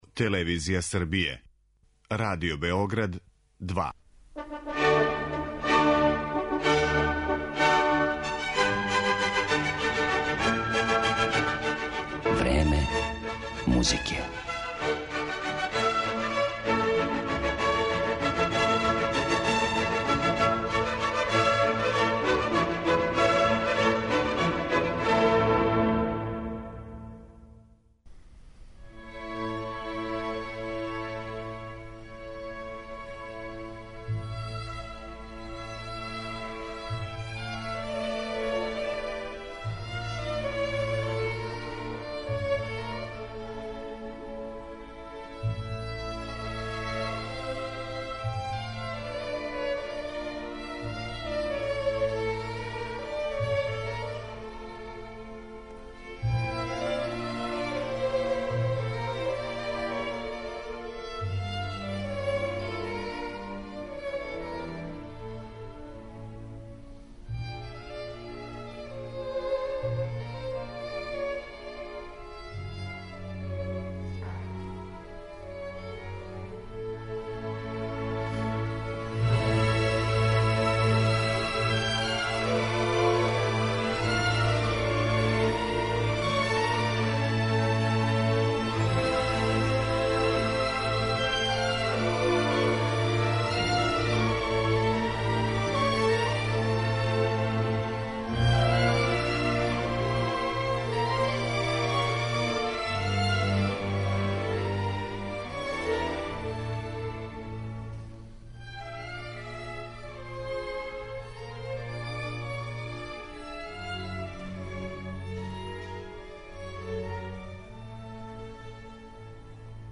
Чућете и разговор са уметником забележен пре неколико година на гостовању у Београду.